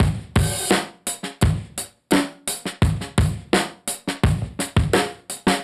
Index of /musicradar/dusty-funk-samples/Beats/85bpm/Alt Sound